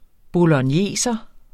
Udtale [ bolʌnˈjeˀsʌ ]